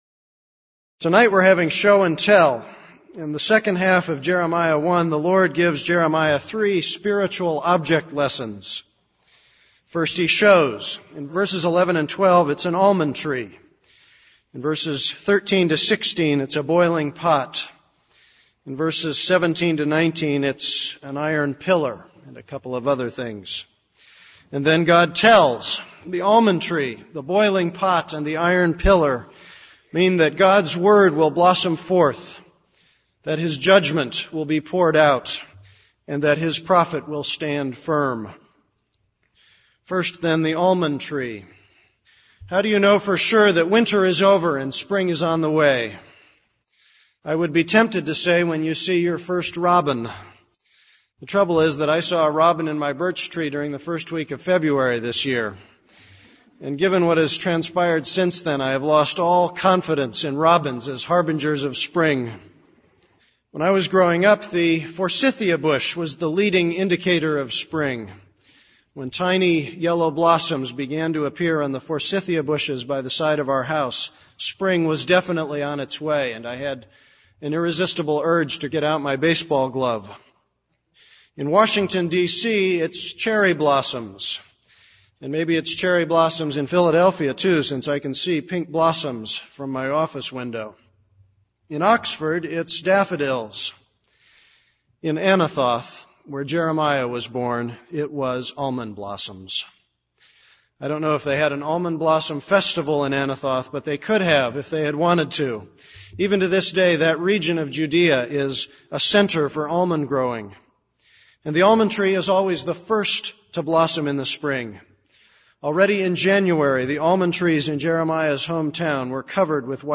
This is a sermon on Jeremiah 1:11-19.